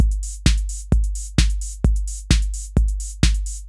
Match Point Beat 2_130.wav